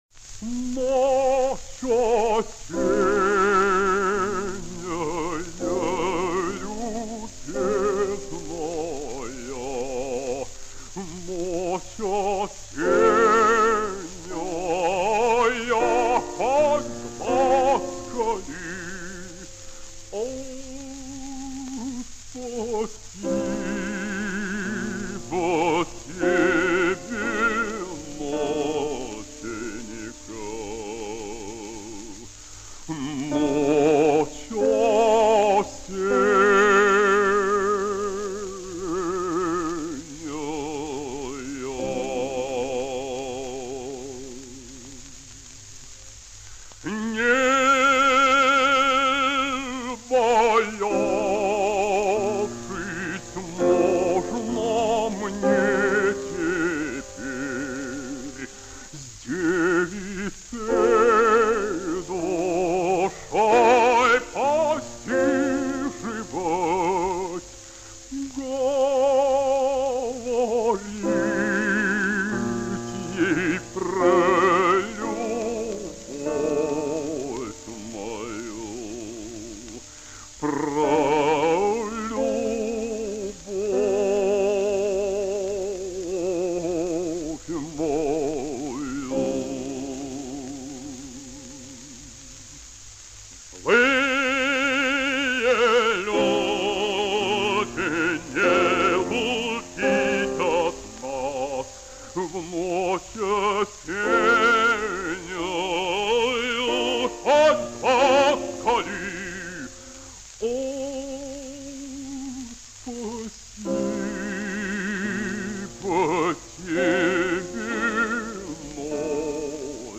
О́гнивцев Алекса́ндр Па́влович (27.8.1920, посёлок Петрово-Красноселье Донецкой губернии, Украинская ССР, ныне г. Петровское, Луганская Народная Республика – 7.9.1981, Москва; похоронен на Новодевичьем кладбище), российский певец (бас), народный артист СССР (1965).
Романс «Ночь осенняя». Исполняет А. П. Огнивцев. Партия фортепиано